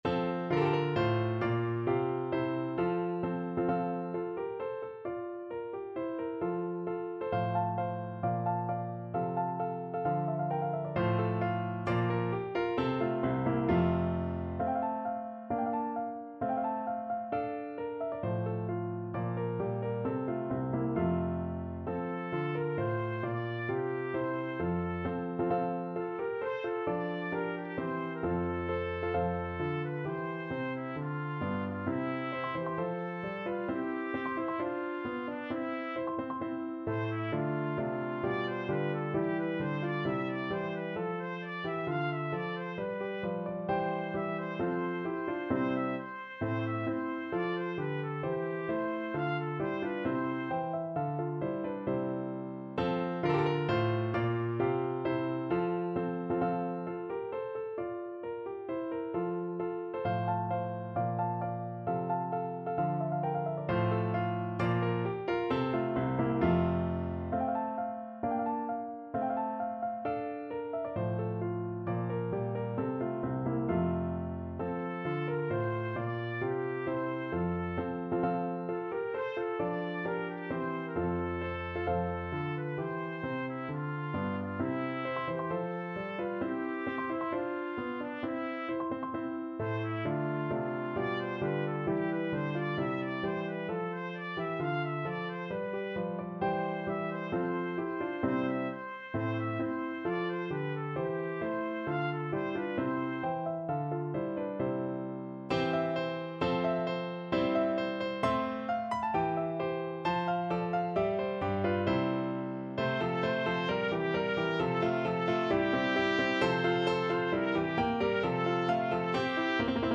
Trumpet version
Score Key: F major (Sounding Pitch)
Time Signature: 4/4
Instrument: Trumpet